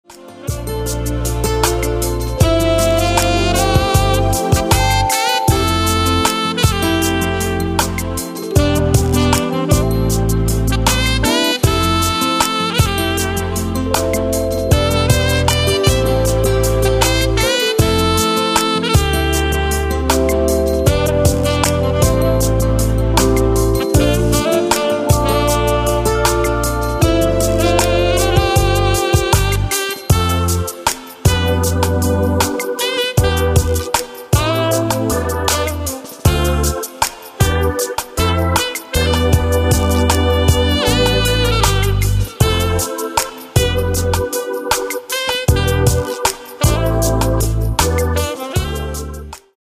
Tenor-Saxophon